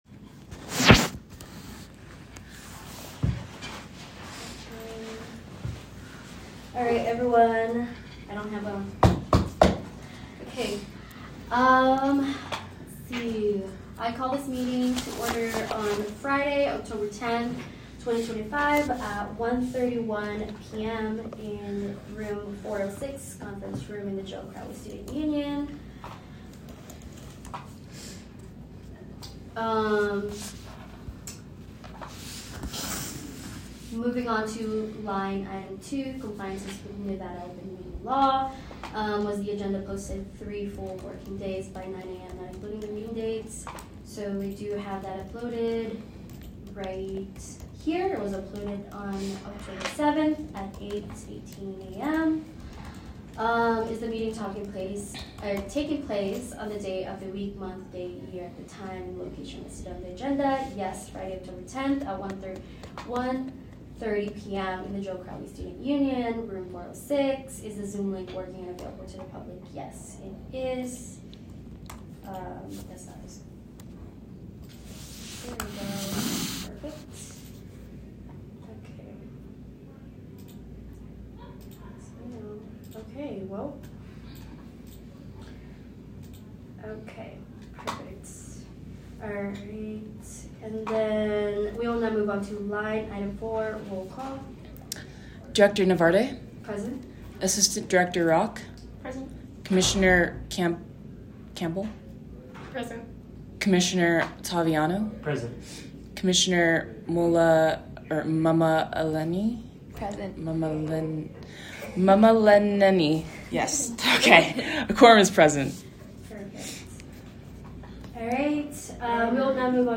Location : Joe Crowley Student Union Room 406 Conference Room